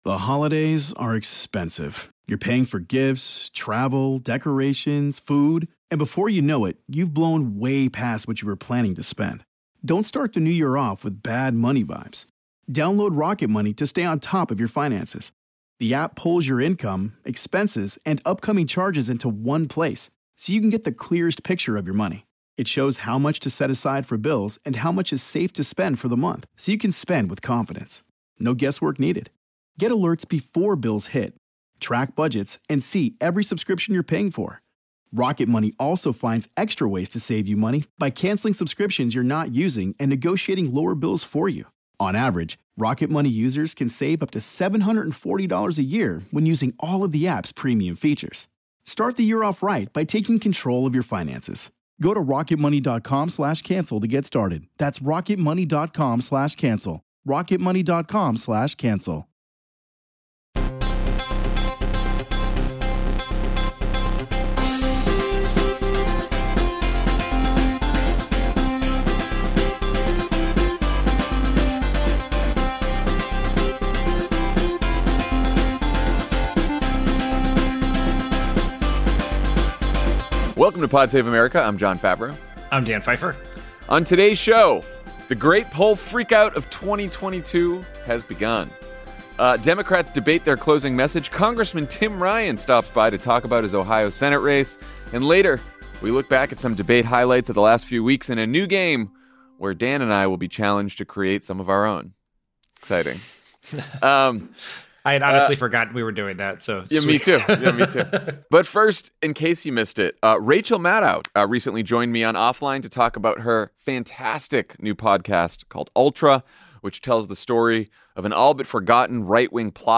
The Great Poll Freakout of 2022 has begun, as Democrats give their closing message to voters. Congressman Tim Ryan stops by to talk about his Ohio Senate race. And Jon and Dan look back at the recent debates in a game that demands they say something nice!